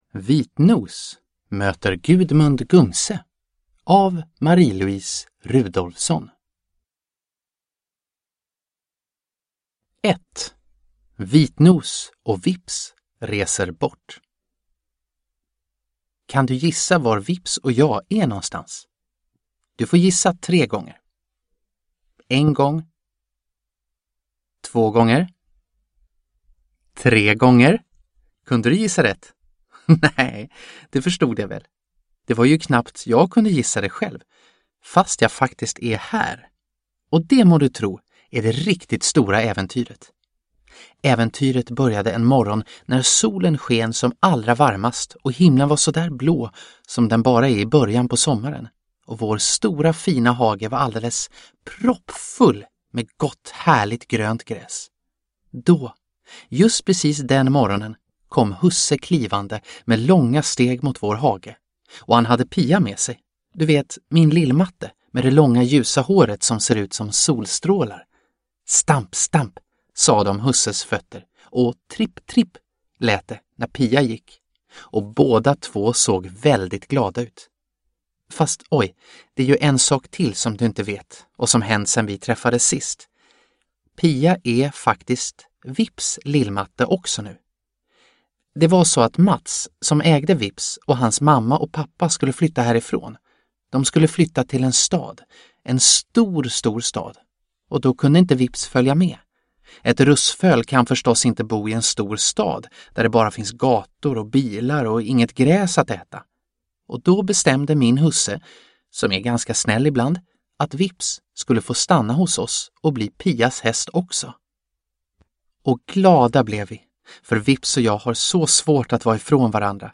Vitnos möter Gudmund Gumse – Ljudbok – Laddas ner